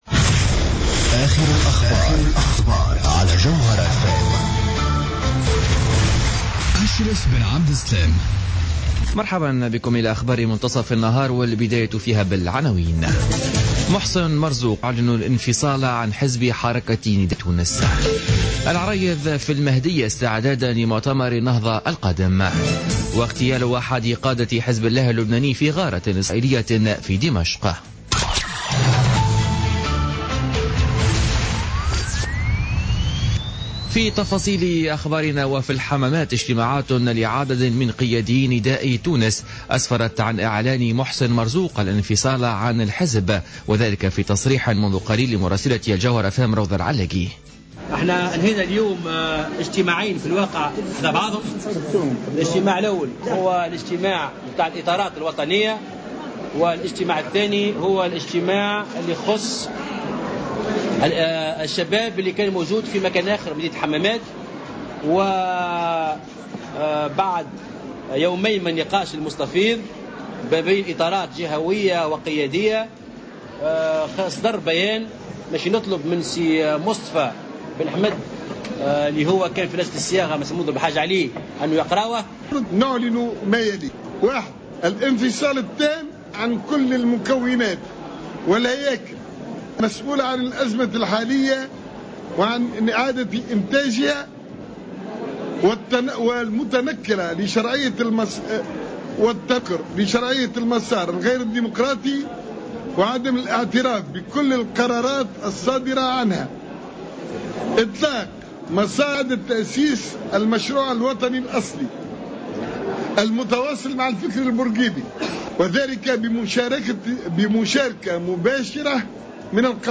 نشرة أخبار منتصف النهار ليوم السبت 19 ديسمبر 2015